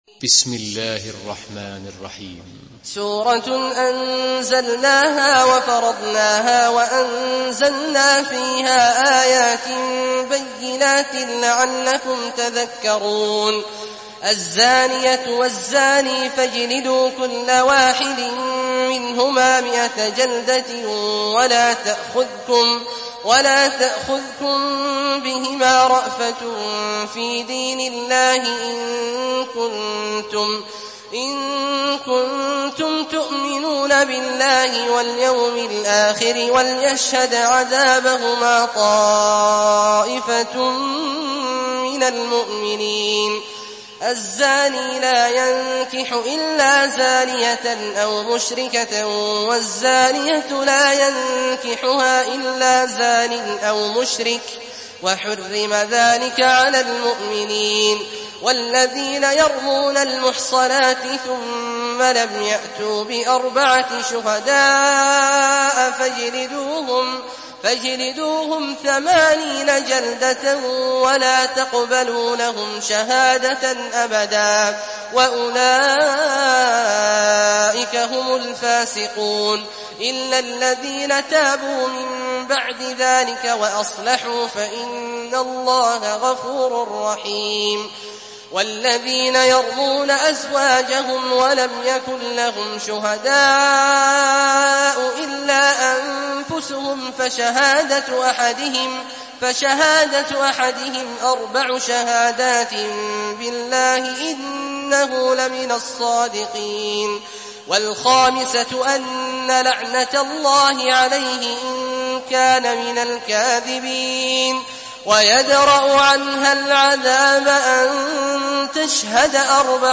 سورة النور MP3 بصوت عبد الله الجهني برواية حفص
مرتل حفص عن عاصم